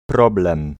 Ääntäminen
IPA: [ˈprɔblɛ̃m]